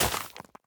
Minecraft Version Minecraft Version latest Latest Release | Latest Snapshot latest / assets / minecraft / sounds / block / nether_sprouts / break4.ogg Compare With Compare With Latest Release | Latest Snapshot